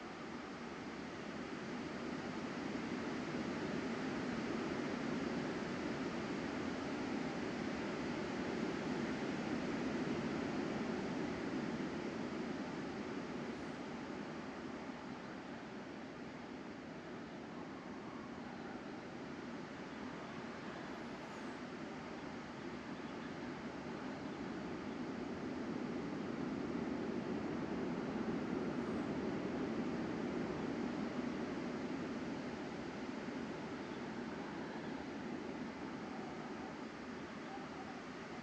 wind-gleba.ogg